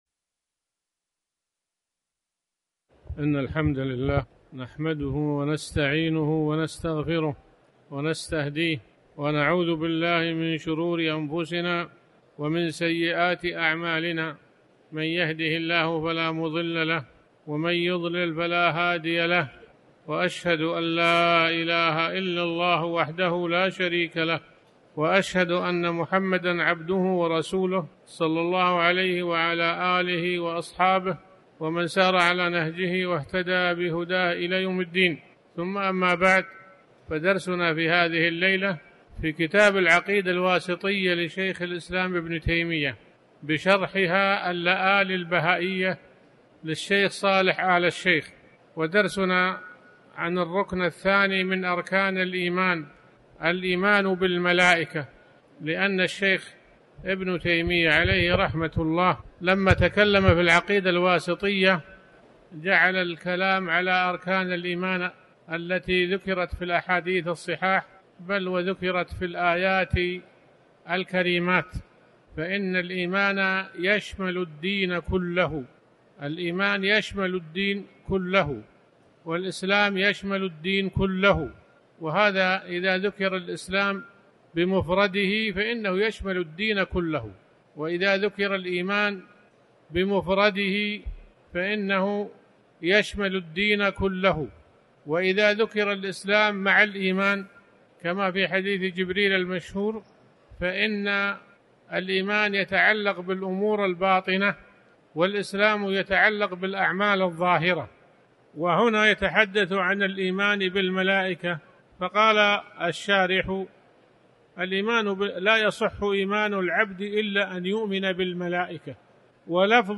تاريخ النشر ١٥ شوال ١٤٤٠ هـ المكان: المسجد الحرام الشيخ